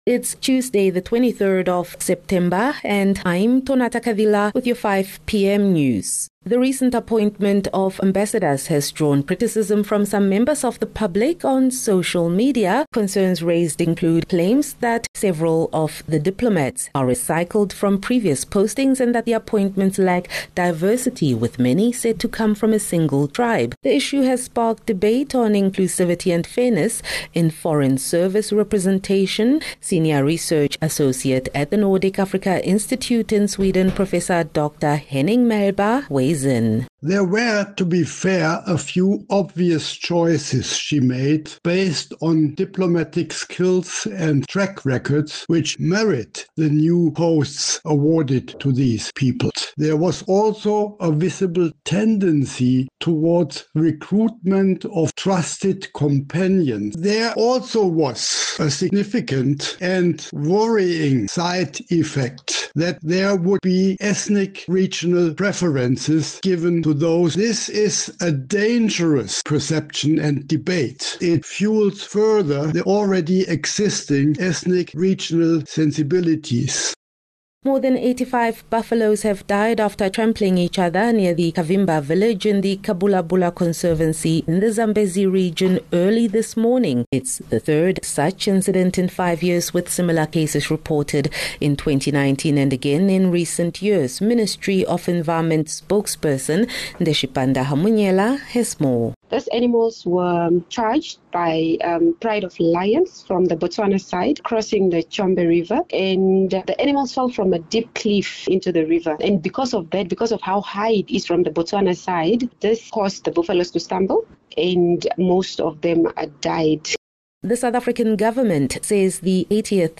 24 Sep 23 September - 5 pm news